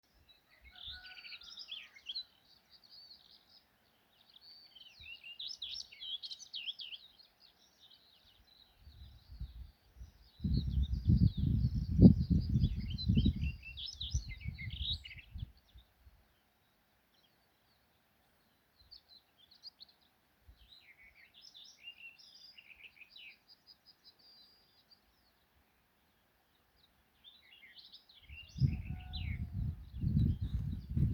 ястребиная славка, Curruca nisoria
СтатусПоёт